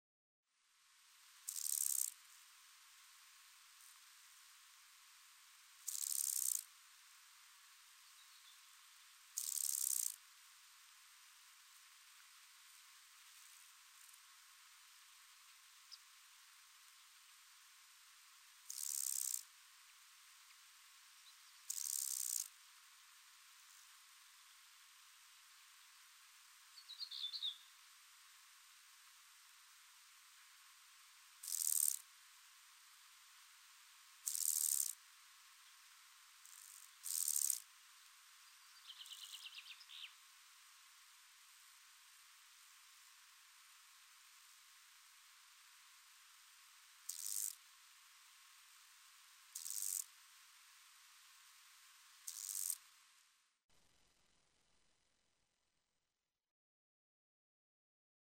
Kaldesang  -
strandengsgræshoppe.mp3